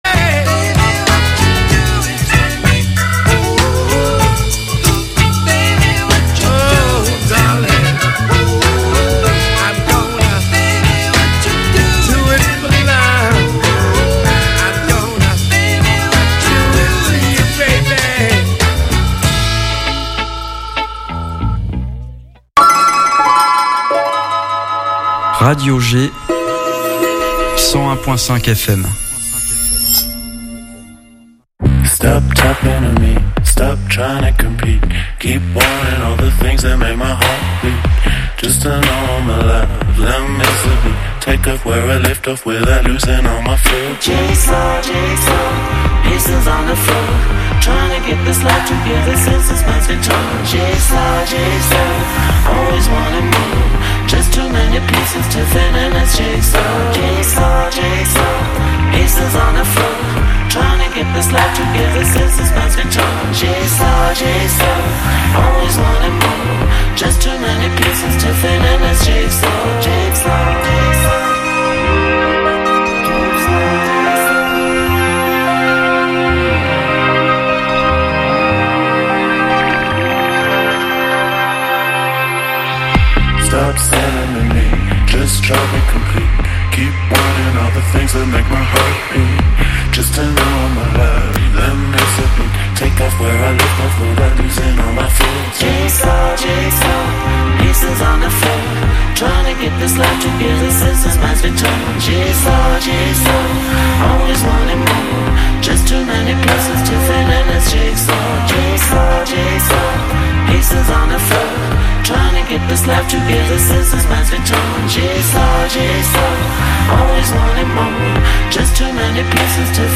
Depuis octobre 1997, Planète Reggae est l'émission purement roots reggae/dub de Radio G!.Plus qu'une émission musicale, Planète Reggae se veut être une sorte de magazine avec des infos sur la culture reggae, jamaïcaine, africaine.